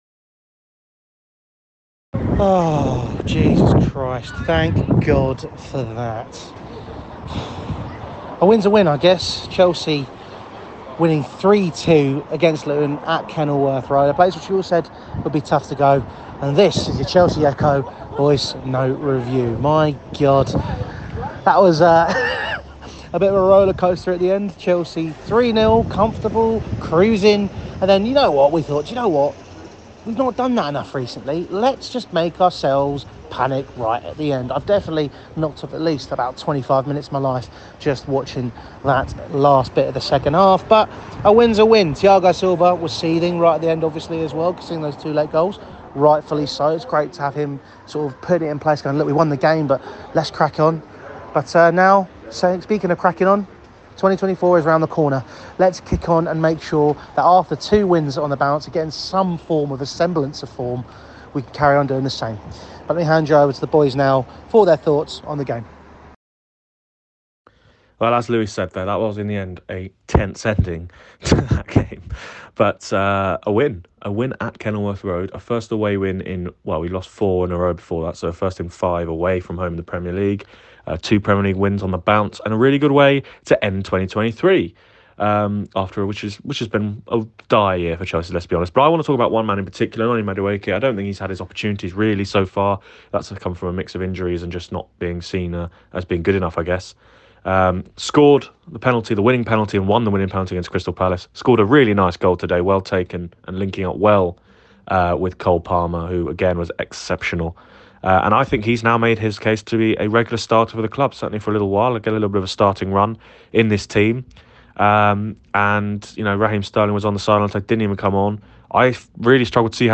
Luton VoiceNote Review